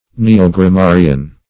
Search Result for " neogrammarian" : The Collaborative International Dictionary of English v.0.48: Neogrammarian \Ne`o*gram*ma"ri*an\, n. [Neo- + grammarian; a translation of G. junggrammatiker.]
neogrammarian.mp3